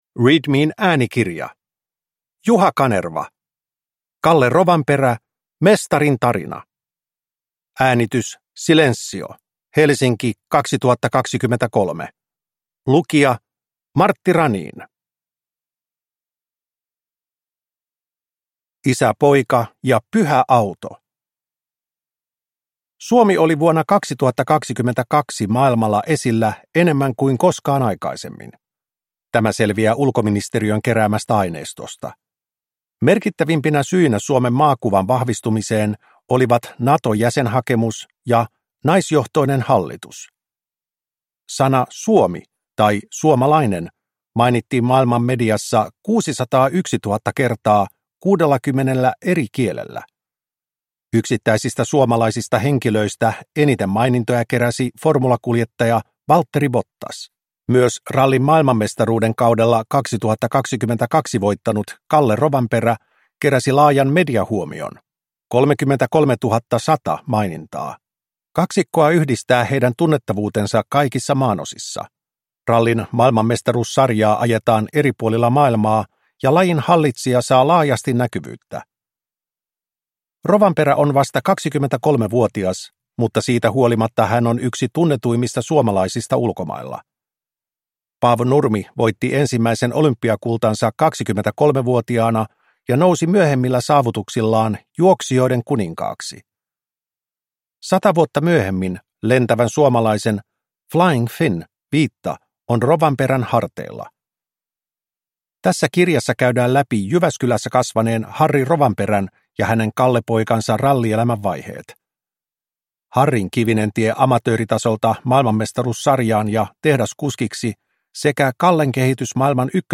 Kalle Rovanperä - Mestarin tarina (ljudbok) av Juha Kanerva